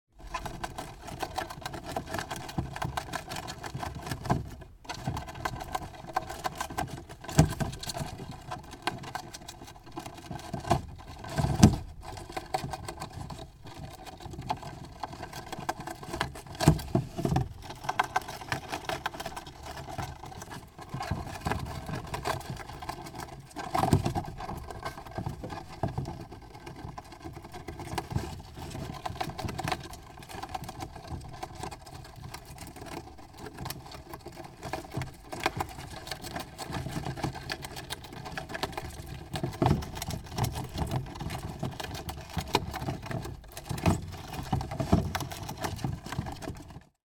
Звук грызуна, заманчиво грызущего картонную коробку